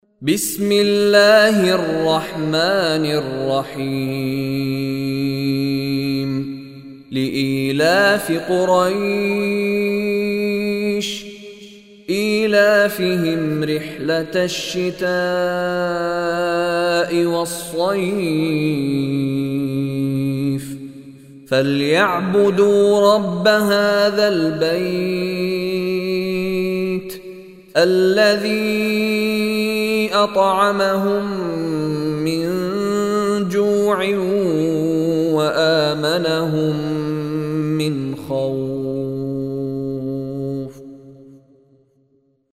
Surah Quraish Recitation by Mishary Rashid Alafasy
Surah Quraish is 106 chapter of Holy Quran. Listen online and download mp3 tilawat / recitation of Surah Quraish in the beautiful voice of Sheikh Mishary Rashid Alafasy.